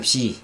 [pçi] number four